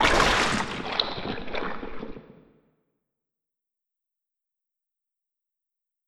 effect__game_over_splatter.wav